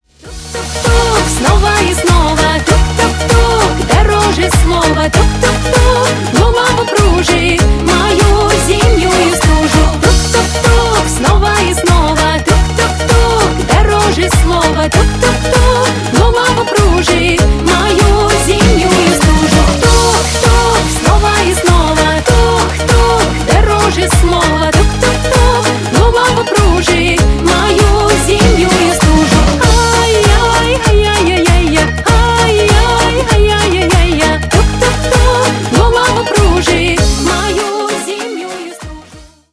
Поп Музыка # Танцевальные
весёлые # кавказские